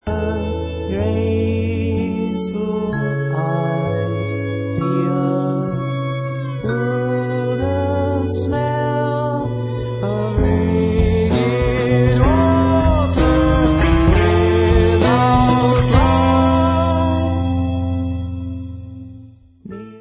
Nahrávalo se ve Zlíně